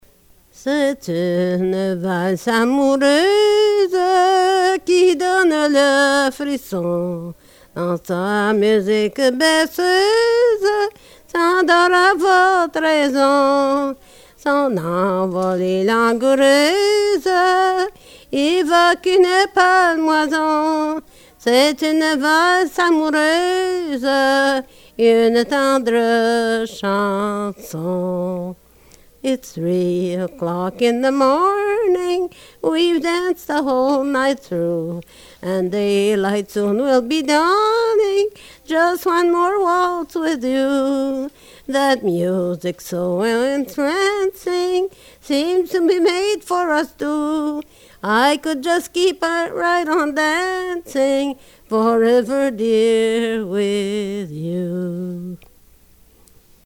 Excerpt from interview
presents a bilingual setting of the chorus of the hit song